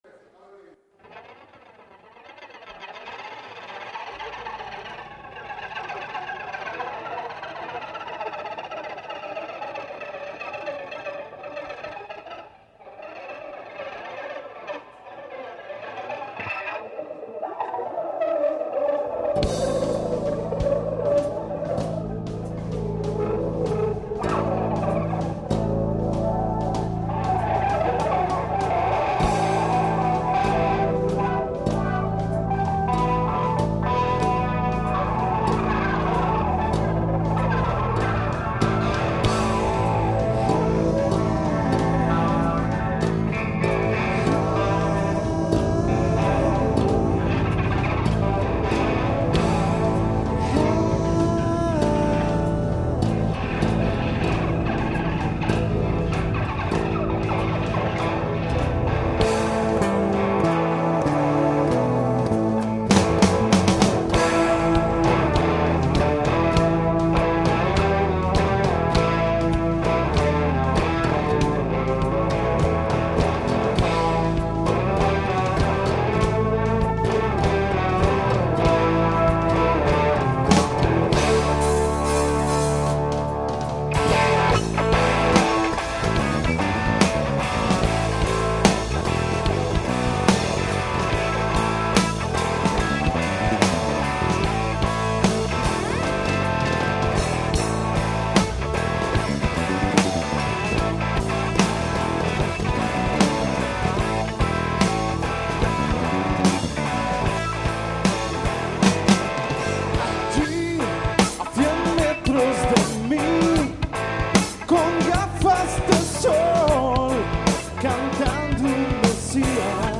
EN DIRECTO